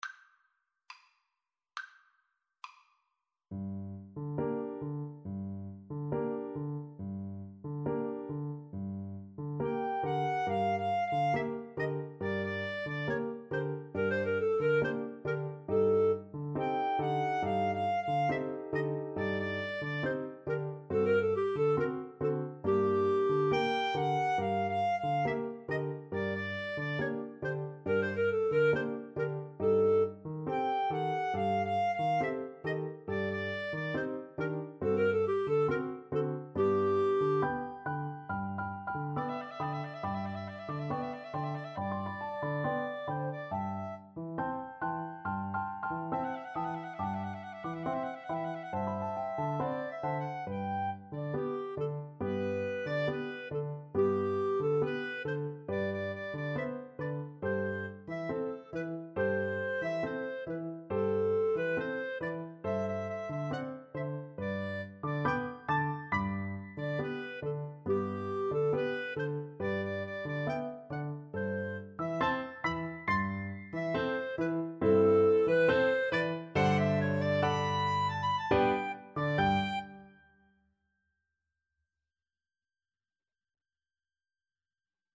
Clarinet 1Clarinet 2Piano
Classical (View more Classical Clarinet Duet Music)